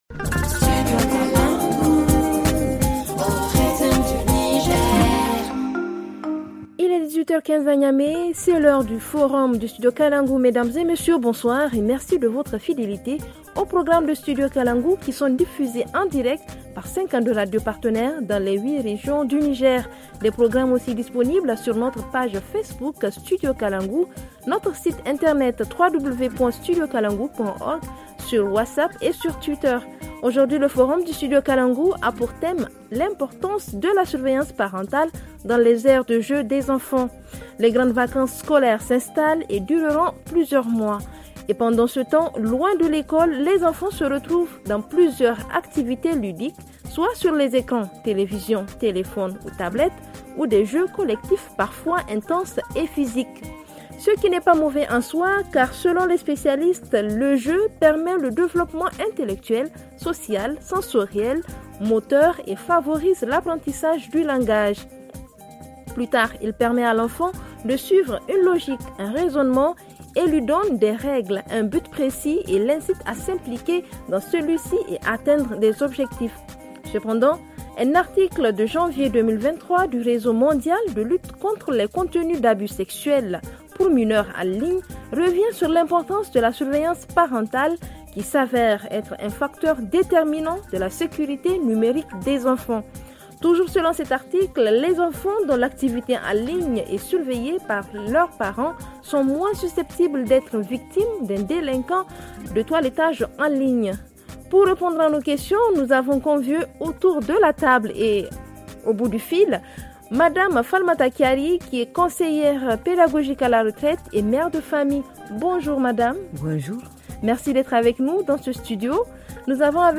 FR Le forum en français Télécharger le forum ici.